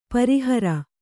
♪ pari hara